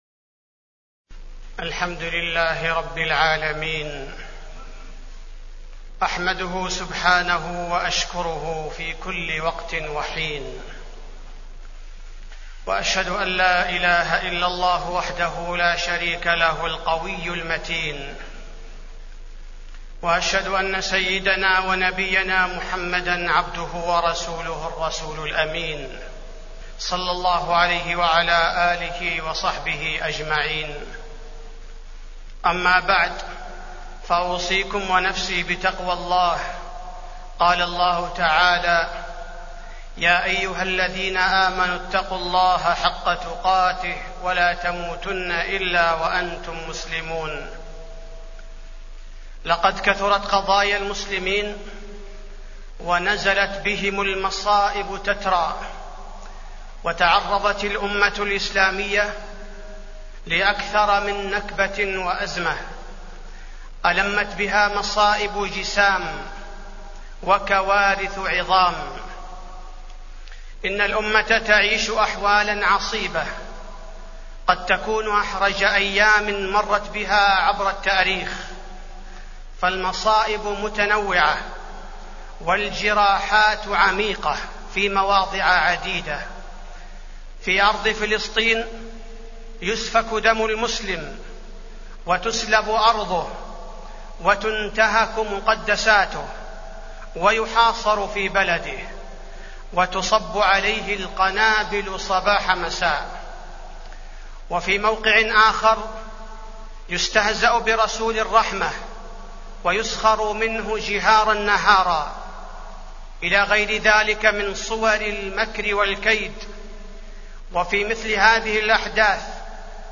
تاريخ النشر ٢٢ صفر ١٤٢٩ هـ المكان: المسجد النبوي الشيخ: فضيلة الشيخ عبدالباري الثبيتي فضيلة الشيخ عبدالباري الثبيتي ما يجب على المسلم عند وقوع الأحداث